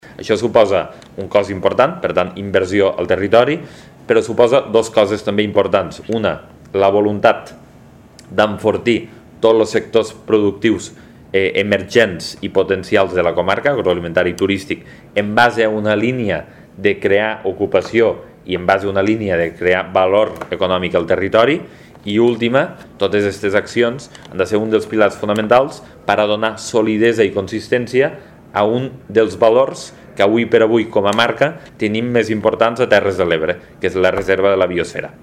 (tall de veu) Lluís Soler explica els objectius de les accions Baix Ebre Avant
decles_soler_-_accions_baixebreavant.mp3